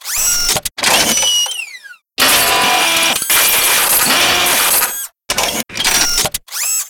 radio-fix.wav